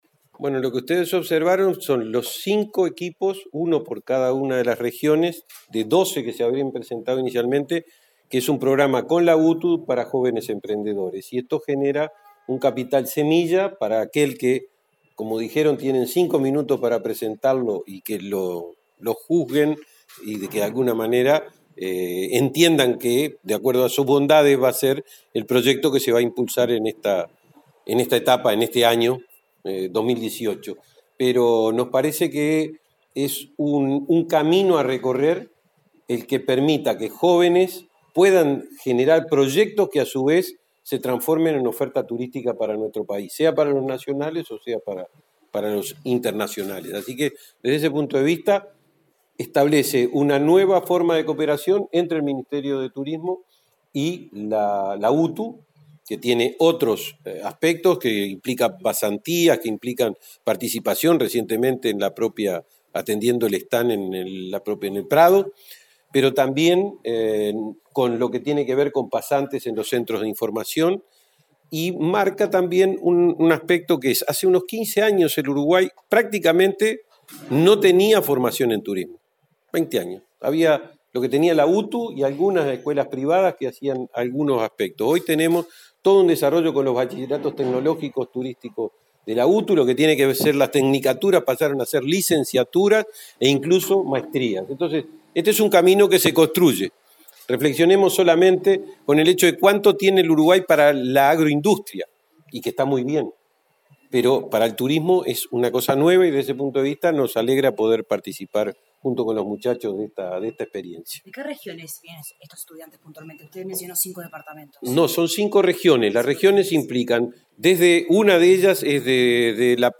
Mediante un acuerdo con el Consejo de Educación Técnico Profesional, se busca promover la creación de nuevas empresas que ofrezcan productos o servicios turísticos, explicó el subsecretario, Benjamín Liberoff.